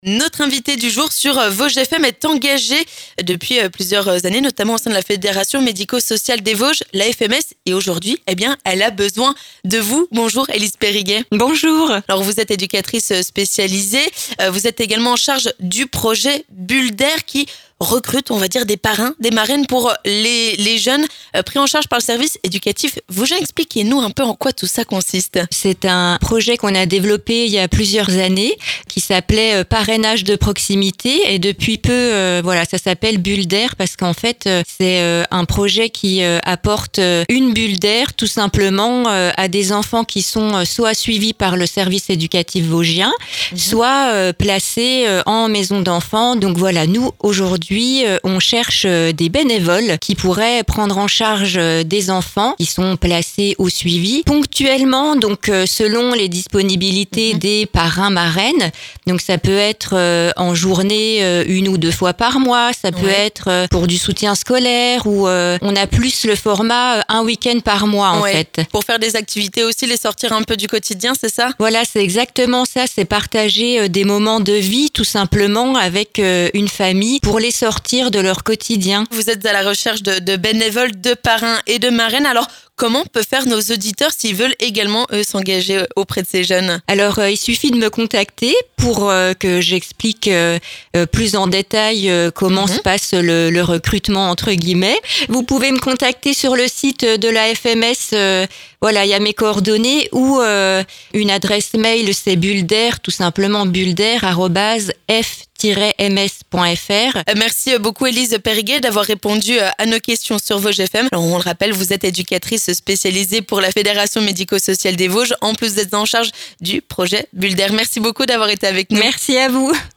Notre invitée du jour a besoin de vous sur Vosges FM.